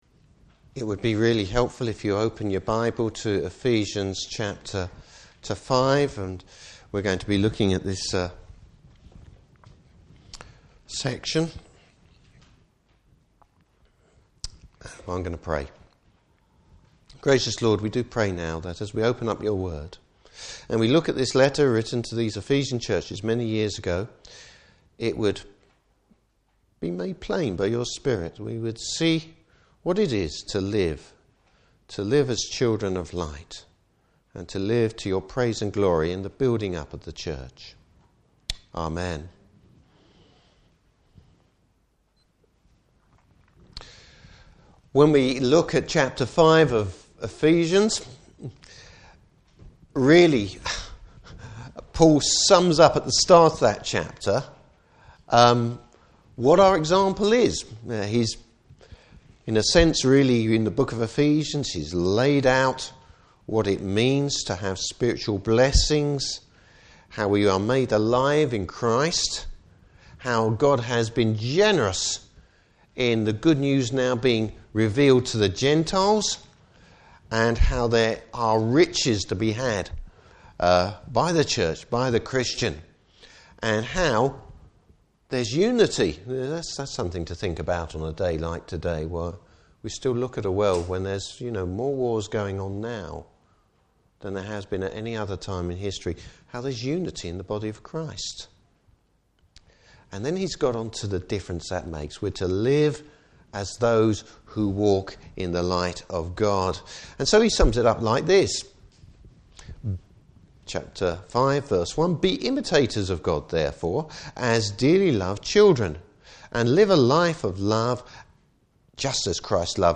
Service Type: Morning Service Bible Text: Ephesians 5:3-20.